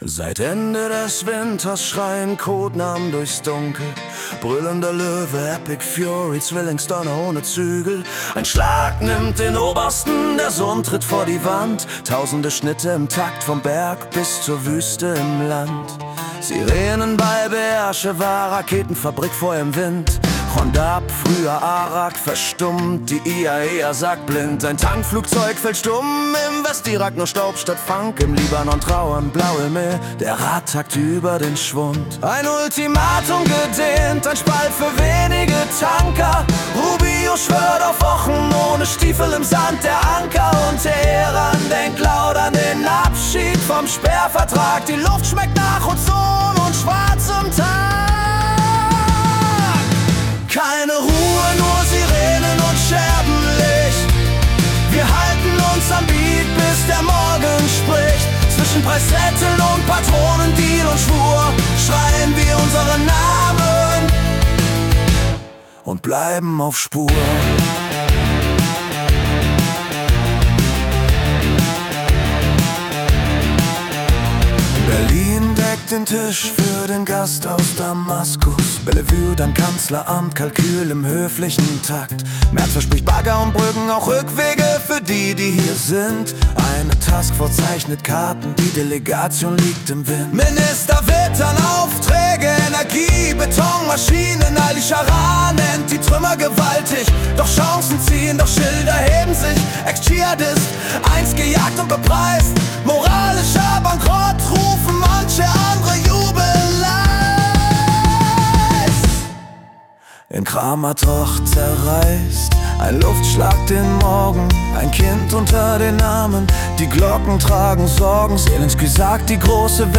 Die Nachrichten vom 31. März 2026 als Rock-Song interpretiert.
Jede Folge verwandelt die letzten 24 Stunden weltweiter Ereignisse in eine mitreißende Rock-Hymne. Erlebe die Geschichten der Welt mit fetzigen Riffs und kraftvollen Texten, die Journalismus...